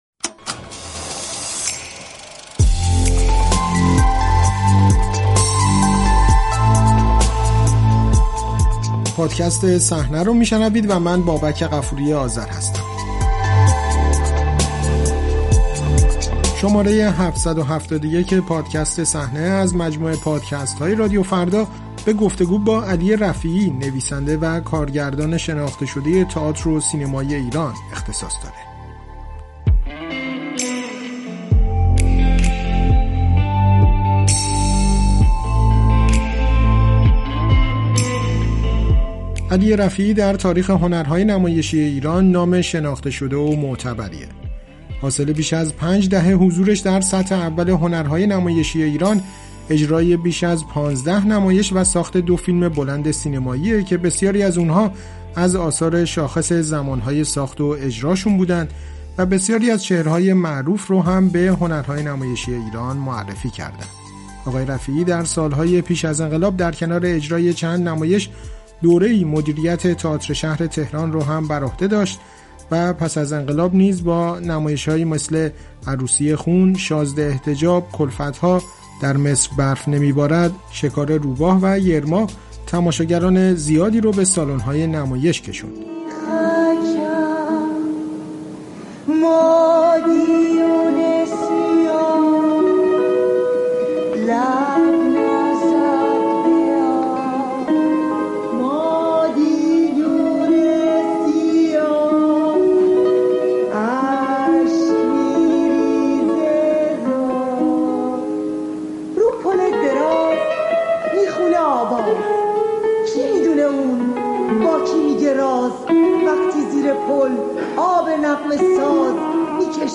گفت‌وگو با علی رفیعی: در قبال جوان‌های ایران بی‌رحمی زیادی می‌شود